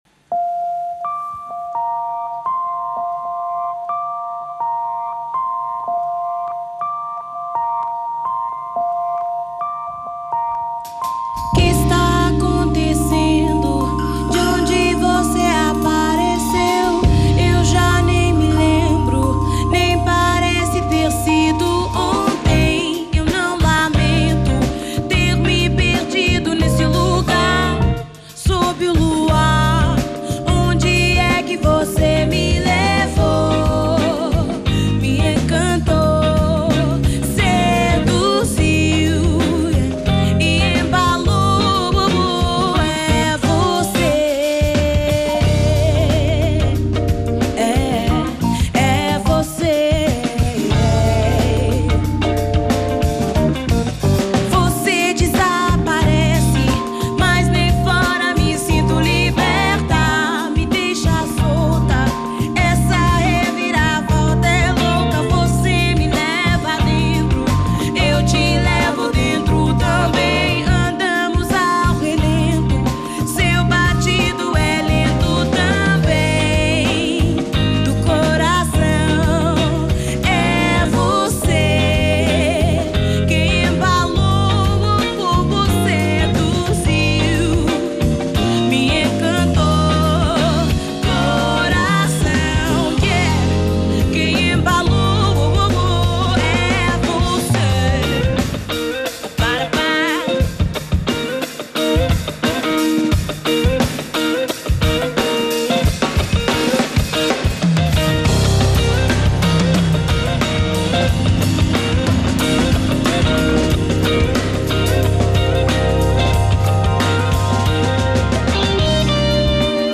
Soul/R&B/Funk
the luxuriant, sensual vocals